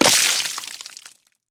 gore3.ogg